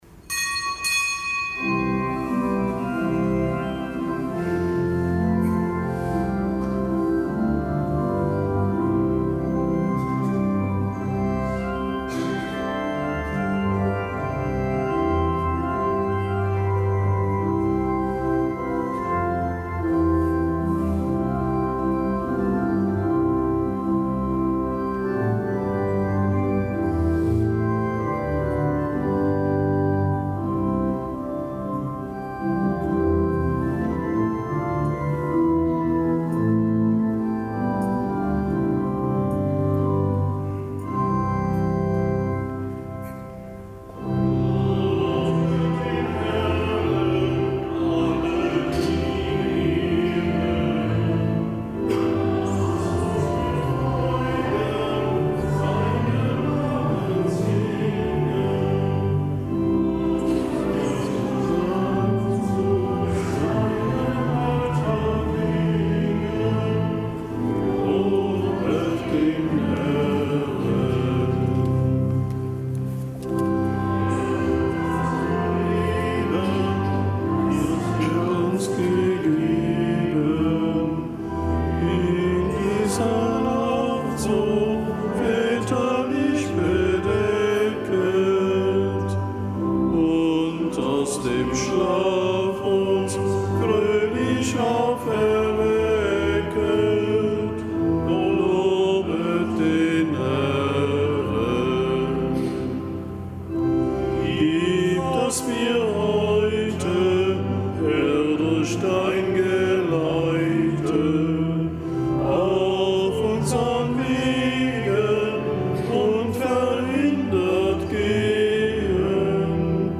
Kapitelsmesse aus dem Kölner Dom am Freitag der dreißigsten Woche im Jahreskreis, dem nicht gebotenen Gedenktag (RK) des Heiligen Wolfgang, einem Bischof von Regensburg.